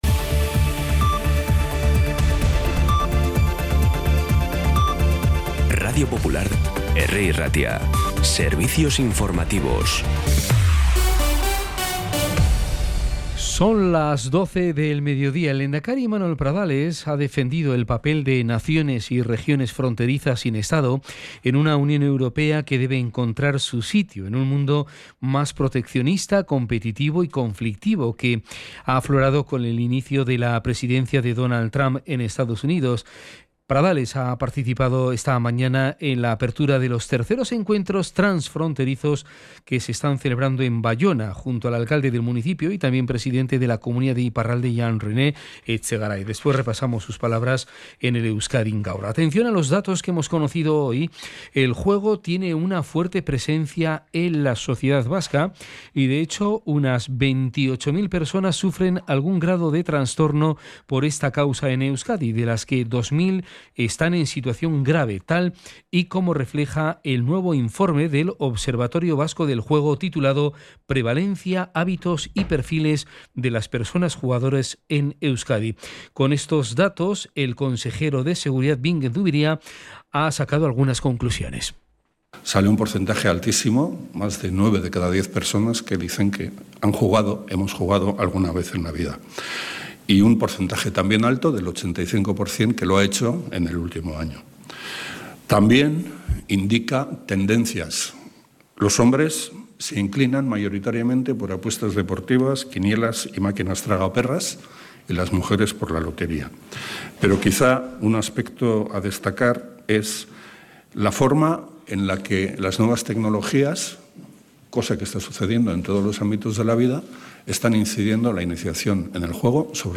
Las noticias de Bilbao y Bizkaia del 12 de febrero a las 12
Los titulares actualizados con las voces del día.